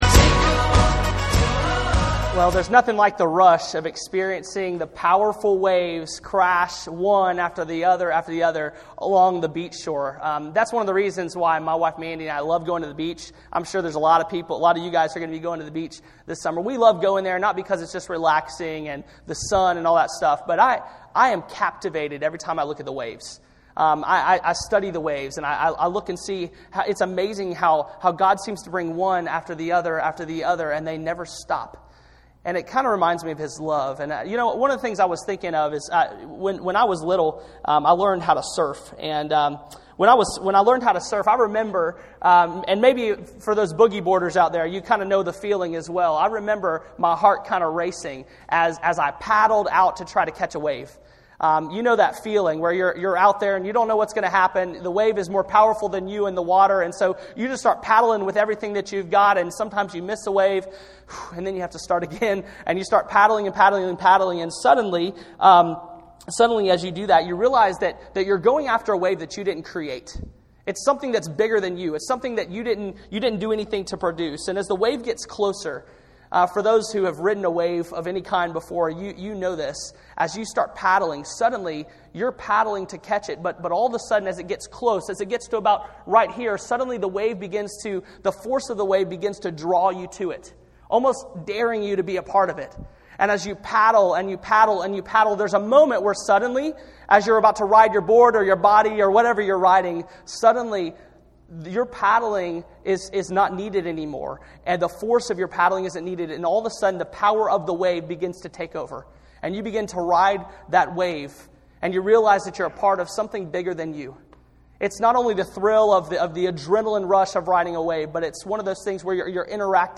SERMON SERIES Waves Of Grace The ocean is a great metaphor for God's grace in your life.